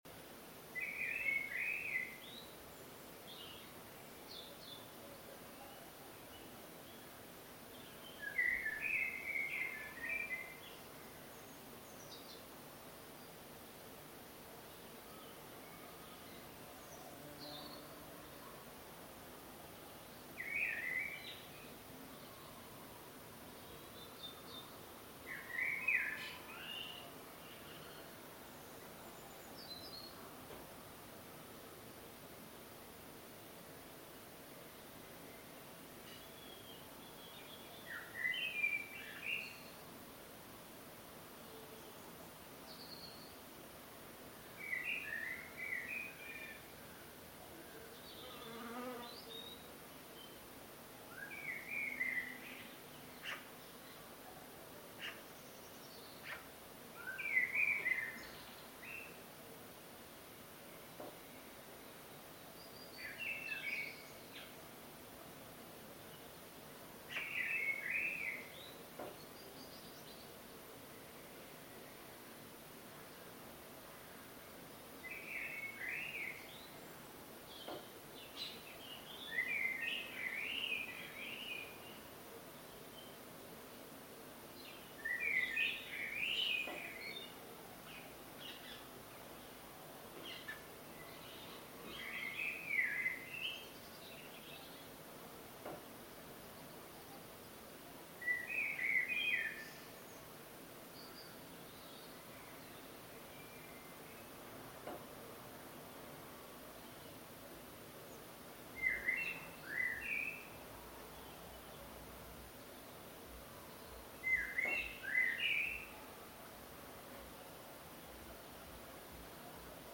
Evening birdsong, Mainly blackbird, 18 April 2021
I heard this blackbird outside my window on Sunday evening and recorded its beautiful song.